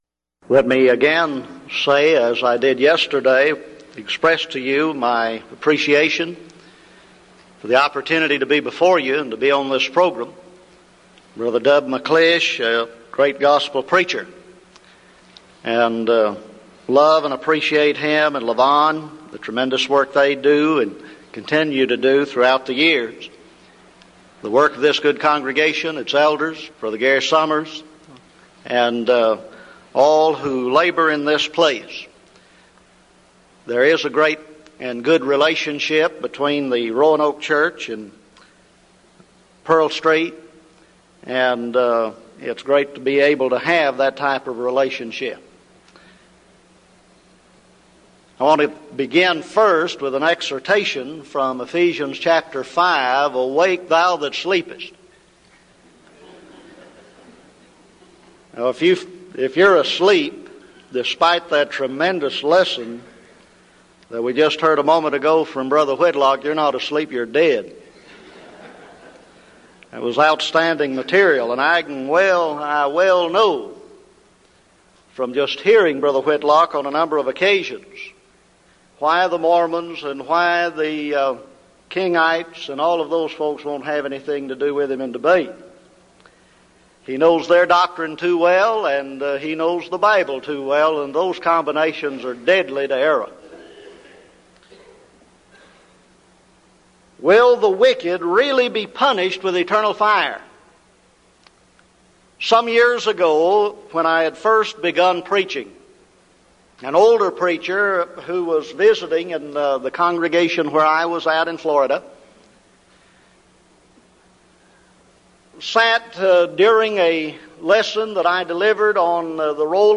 Title: DISCUSSION FORUM: Will the Wicked Really Be Punished with Eternal Fire?
Event: 1998 Denton Lectures